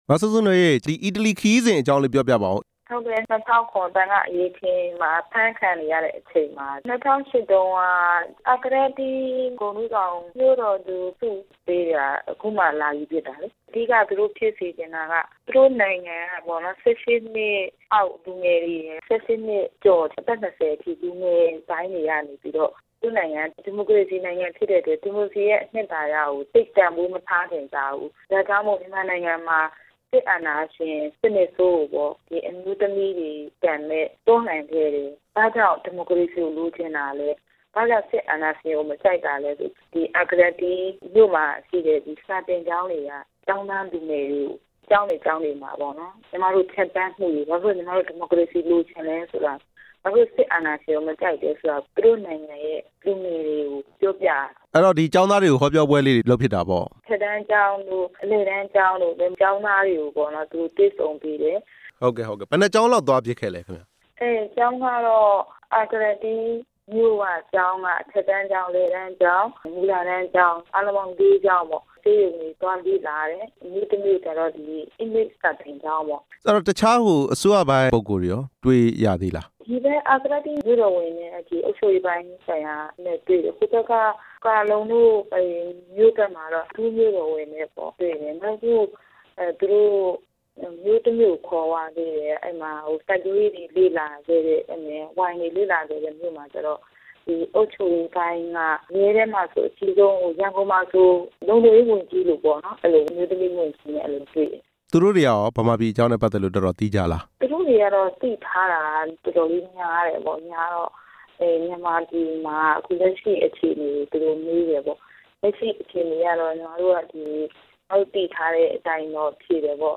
အီတလီနိုင်ငံမှ ဂုဏ်ထူးဆောင်မြို့သူဆုရ မစုစုနွေးနဲ့ မေးမြန်းချက်